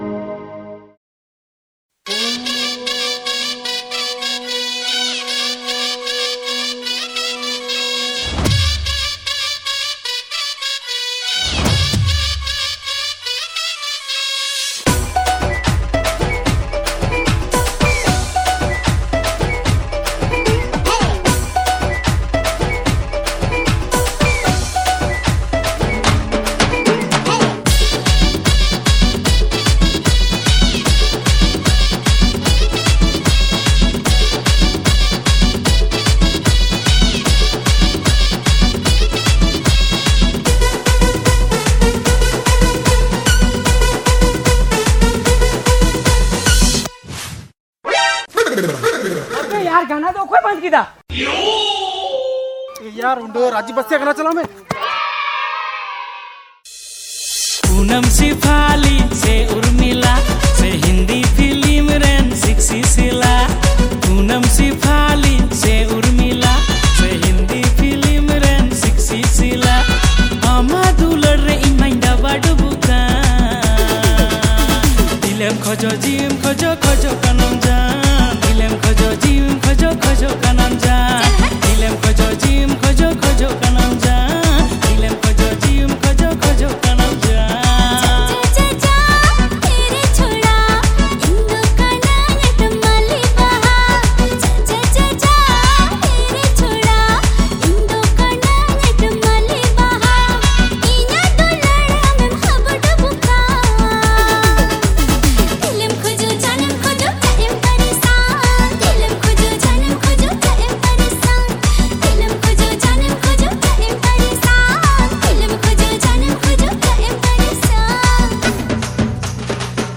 • Male Artist
• Female Artist